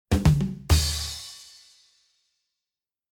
Funny Cartoon Drum Sound Button: Unblocked Meme Soundboard